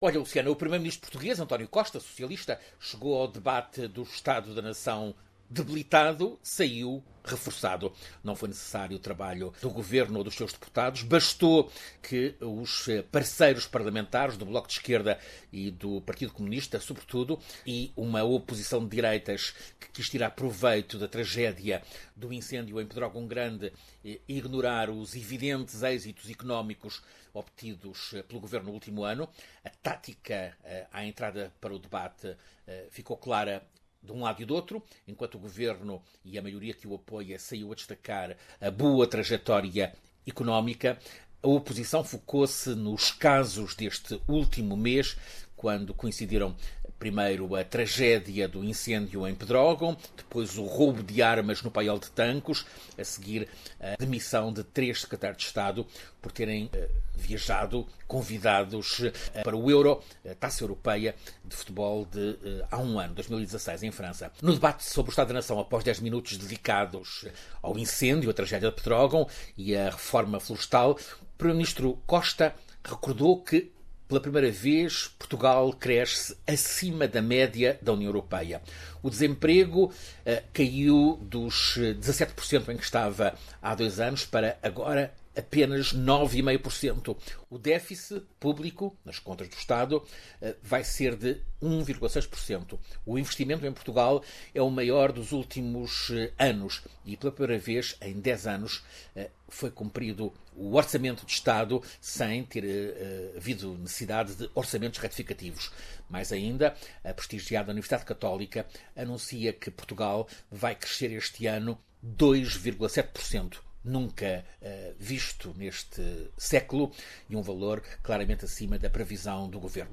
Ouça análise política